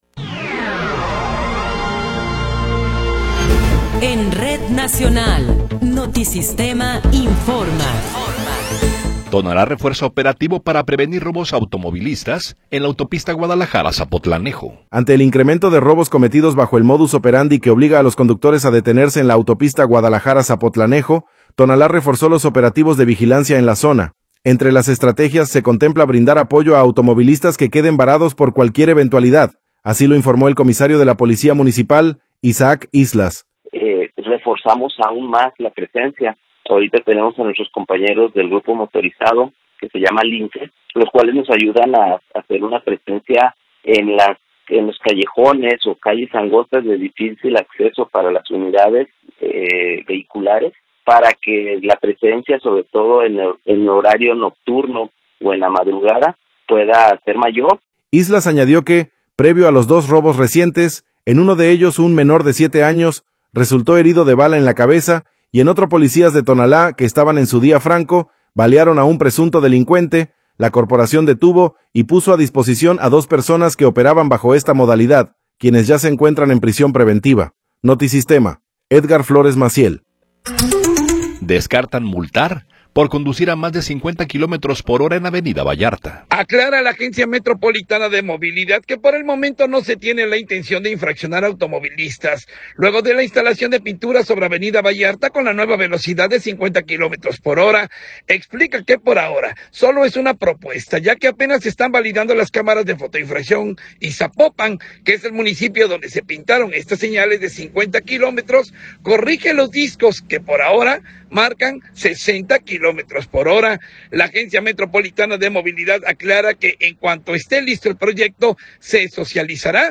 Noticiero 16 hrs. – 8 de Enero de 2026